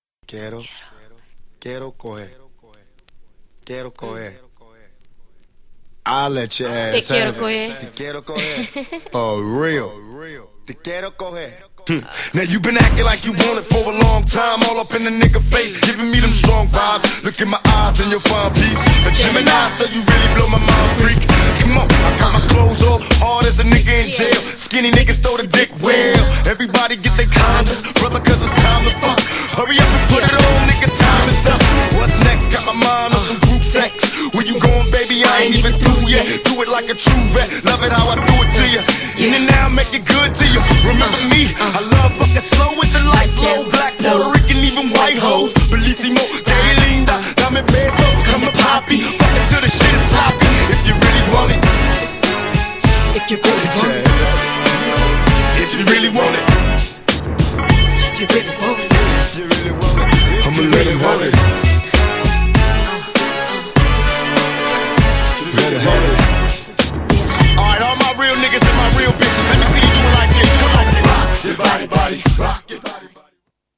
GENRE Hip Hop
BPM 81〜85BPM
SMOOTHなフロウ
スモーキーなトラック # 哀愁系